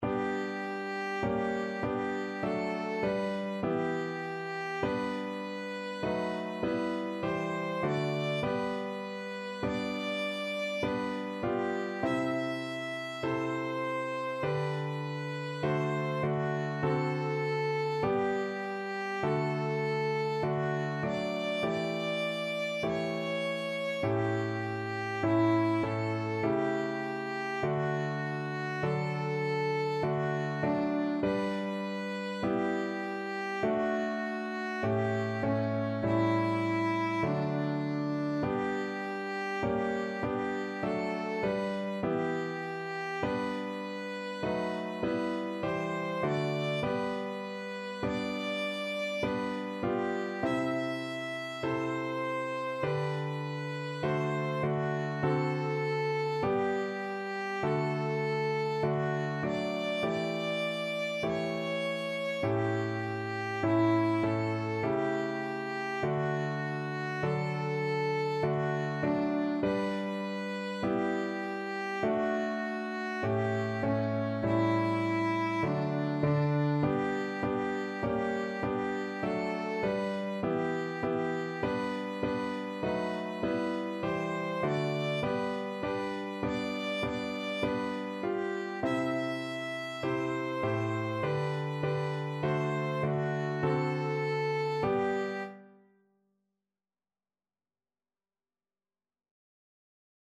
Violin
G major (Sounding Pitch) (View more G major Music for Violin )
4/4 (View more 4/4 Music)
Traditional (View more Traditional Violin Music)
en_kelohenu_VLN.mp3